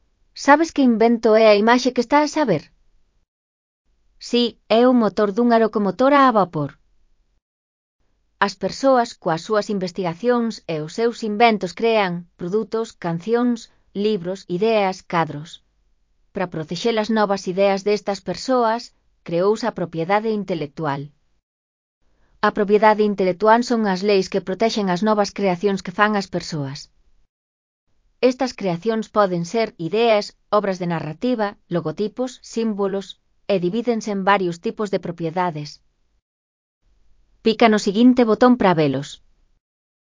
Elaboración propia (proxecto cREAgal) con apoio de IA, voz sintética xerada co modelo Celtia. Locomotora a vapor (CC BY-NC-SA 4.0)